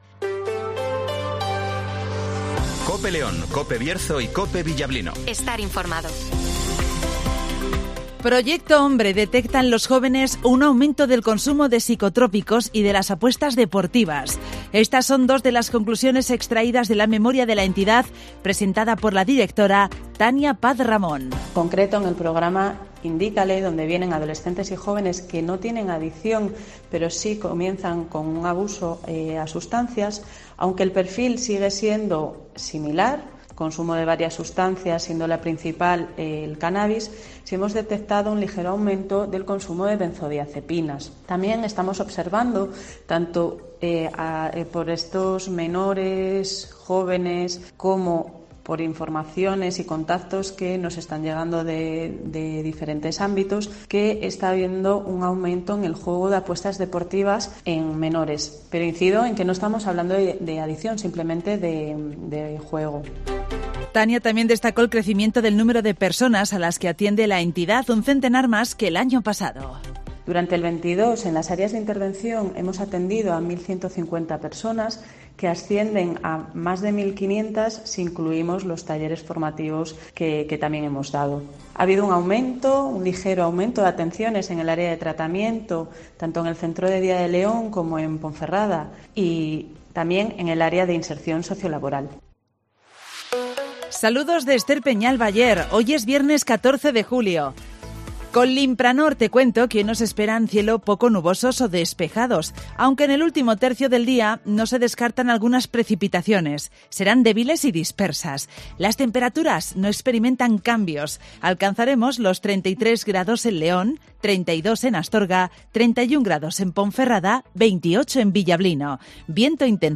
- Informativo Matinal 08:25 h